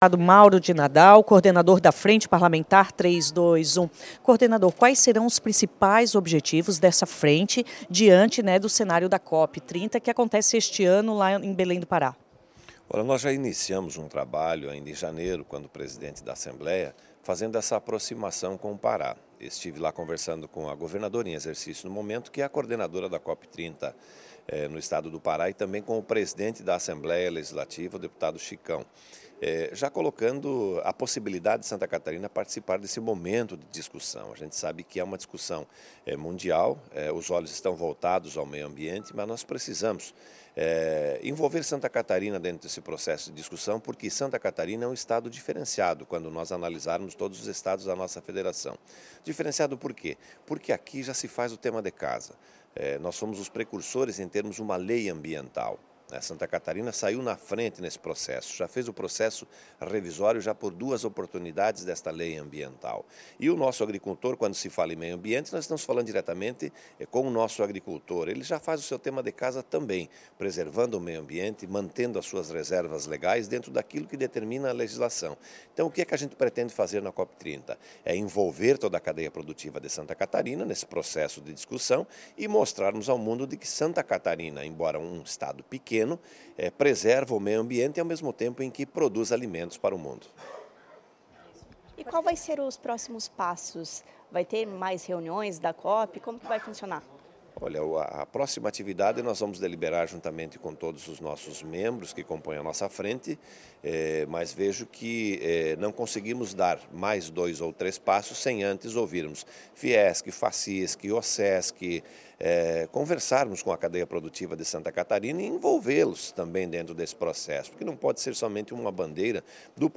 Audio_entrevista_deputado_Mauro.mp3